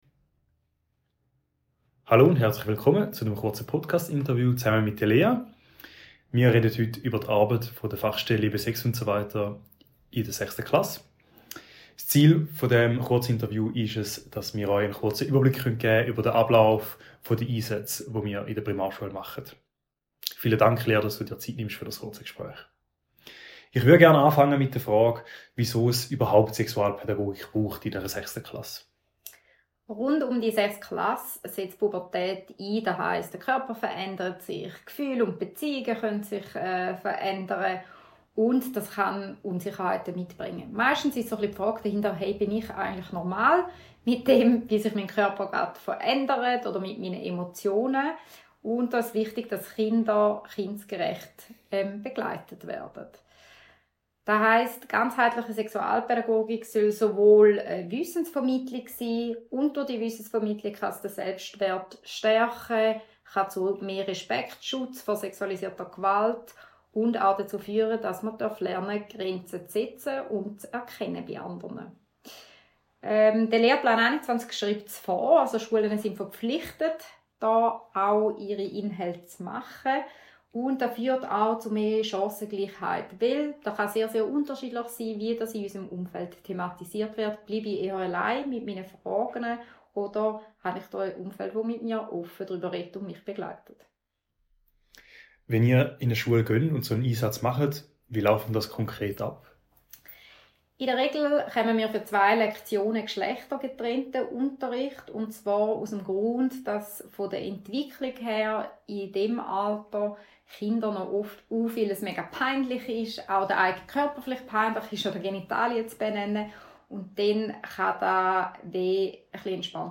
Podcast Interview
podcast-interview.mp3